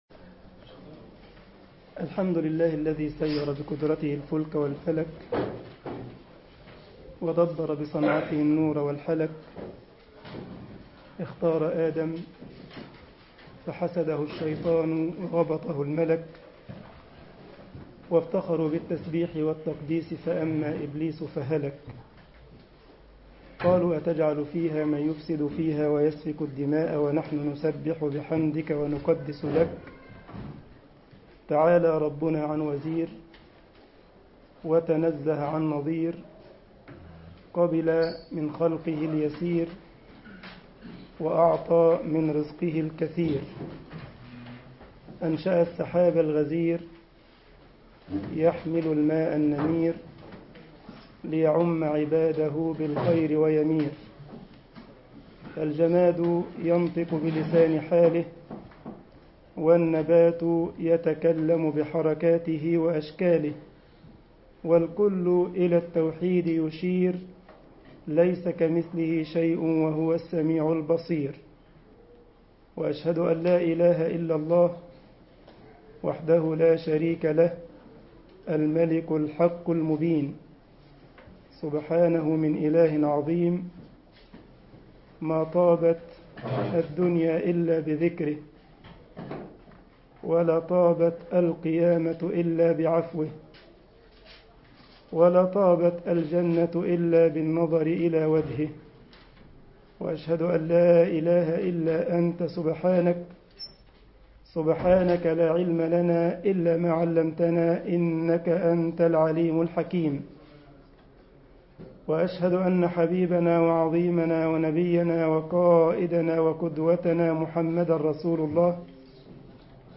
مسجد الجمعية الاسلامية بالسارلند محاضرة